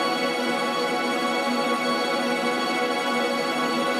GS_TremString-E6+9.wav